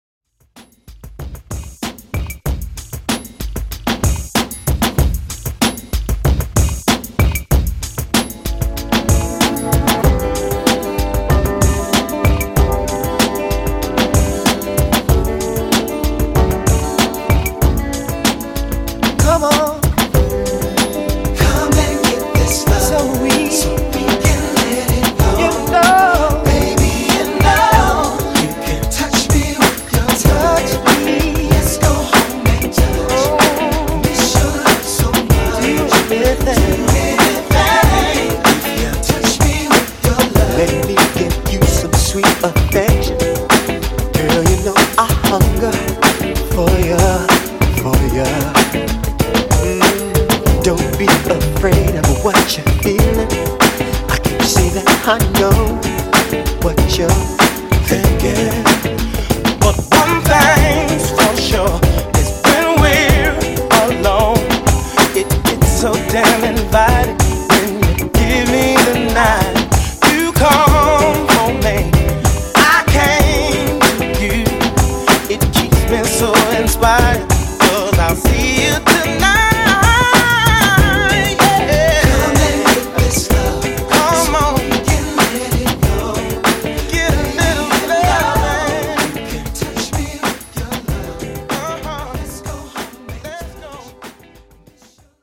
90s RnB ReDrum)Date Added